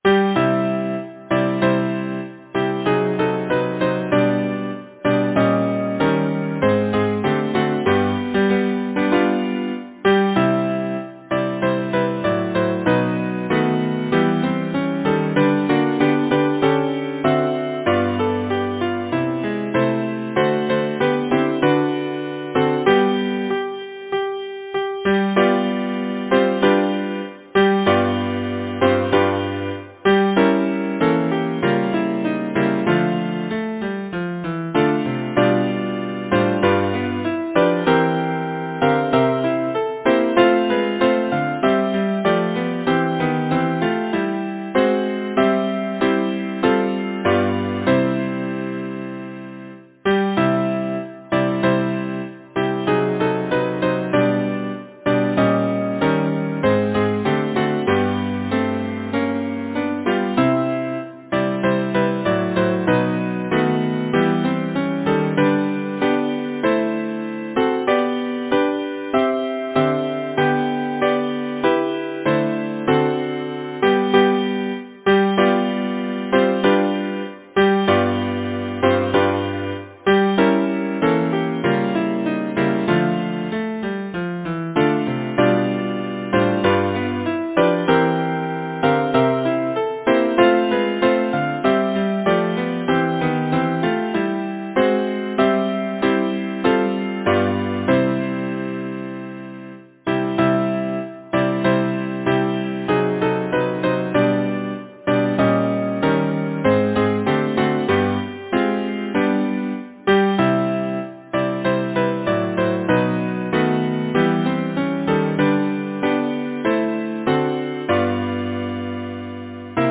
Title: New Year’s song Composer: Berthold Tours Lyricist: Georgina Elizabeth Troutbeck Number of voices: 4vv Voicing: SATB, divisi Genre: Secular, Partsong
Language: English Instruments: A cappella